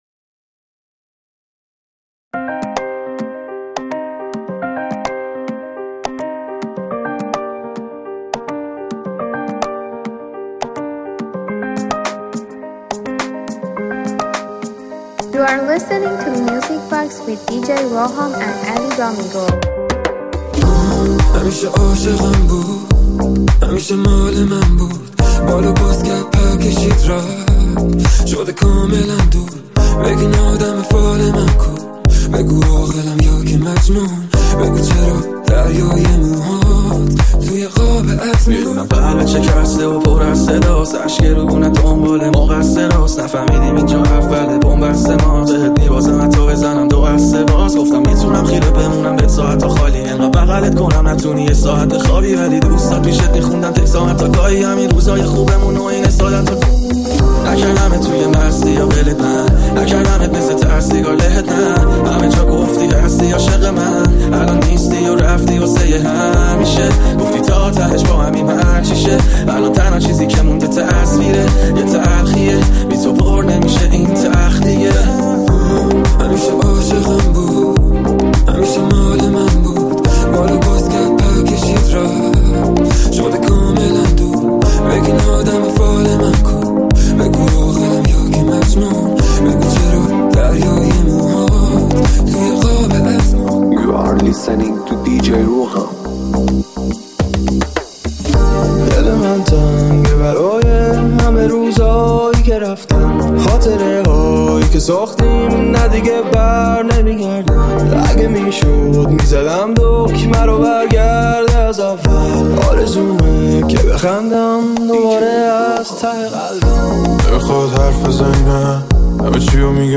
یه ریمیکس فوق‌العاده و پرانرژی